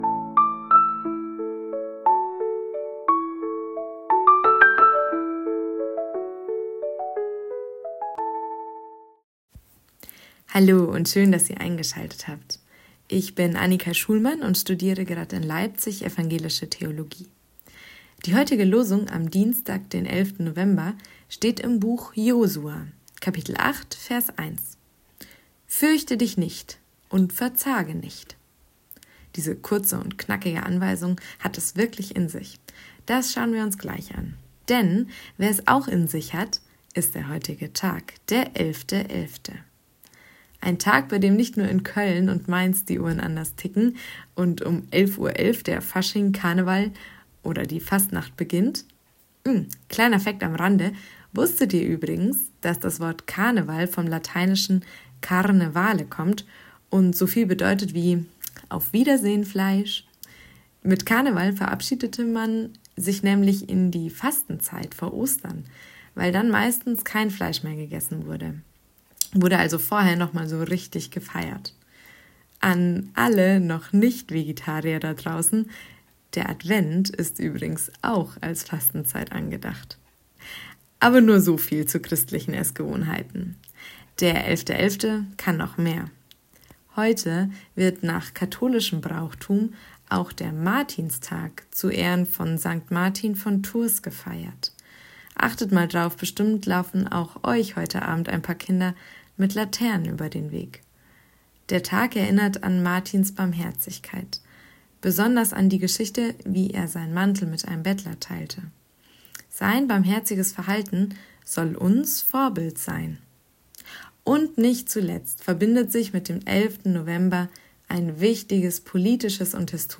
Losungsandachten
Text und Sprecherin